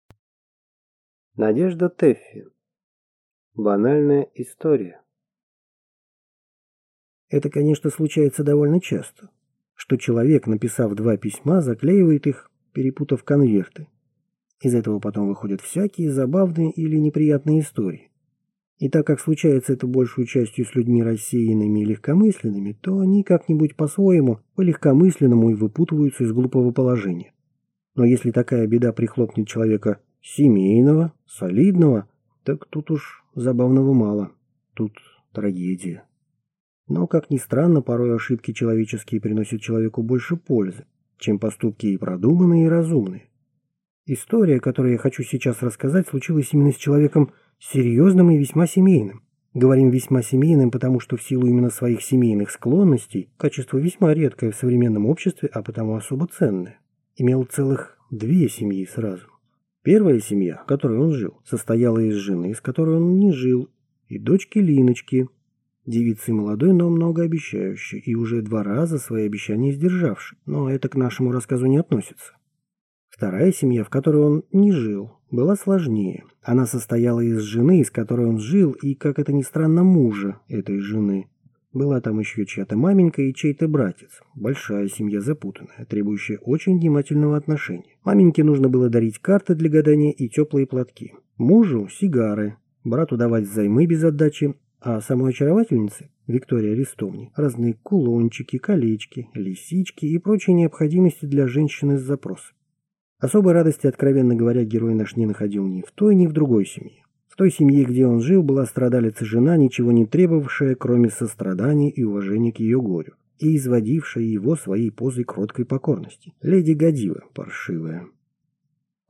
Aудиокнига Банальная история